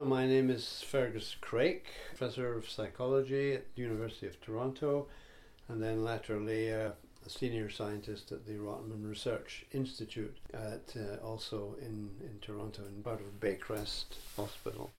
To start, here Dr.  Craik introduces himself: